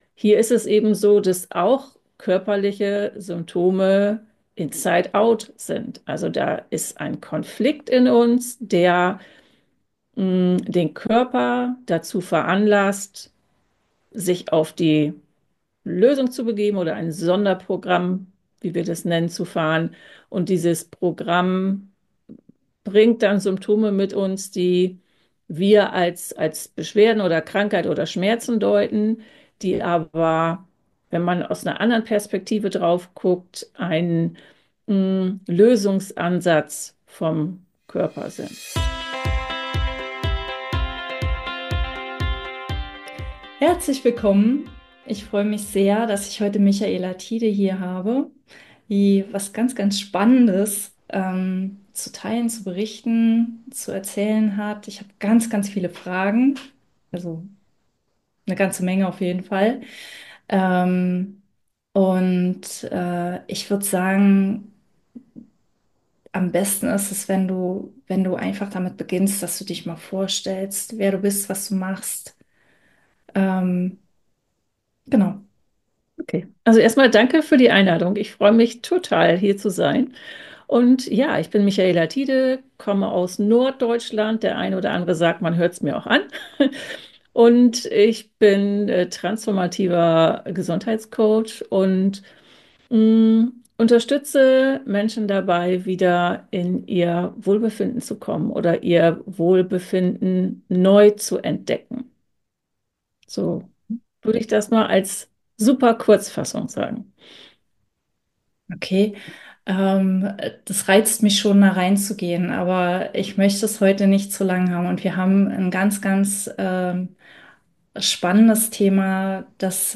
#139 - Selbstheilung - Leichter als du denkst! [Interview] ~ Slow Marketing | Sichtbarkeit und Umsatz auf DEINE Art, mit innerer Weisheit, Strategie & Raum für Wunder Podcast